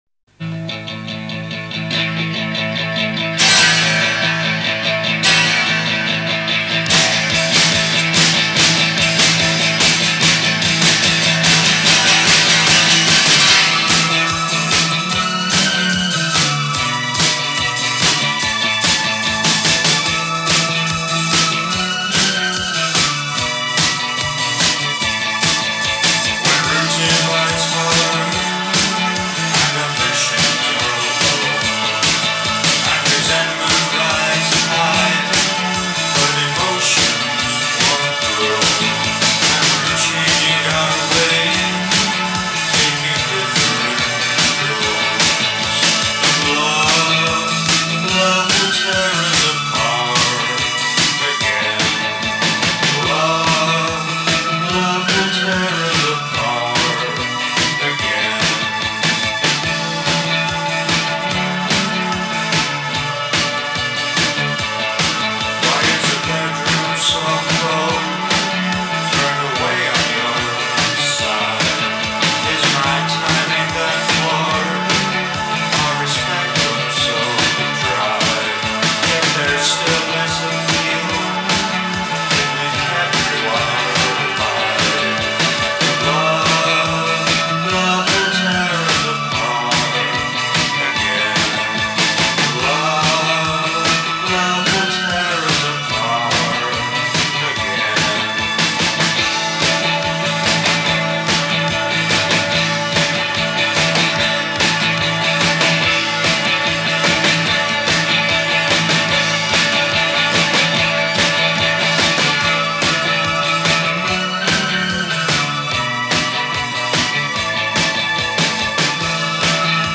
groupe de rock britannique
Oui c’est très noir en effet.